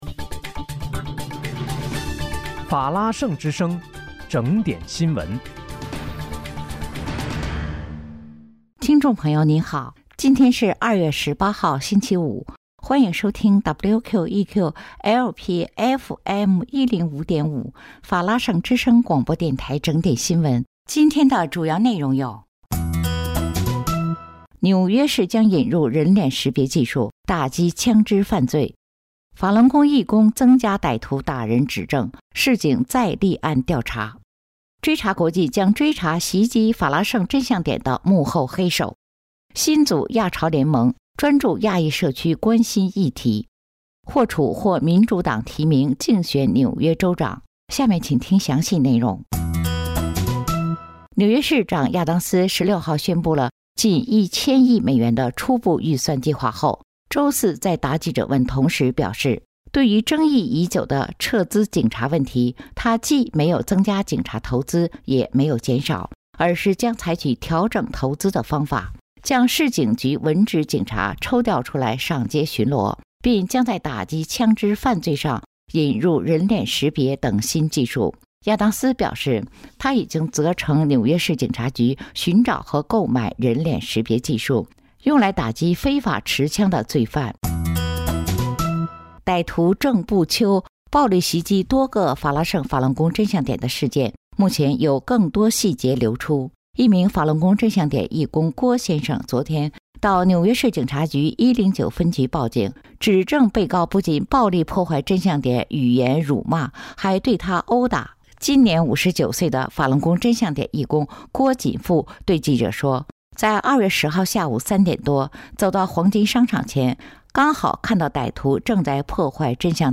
2月18日（星期五）纽约整点新闻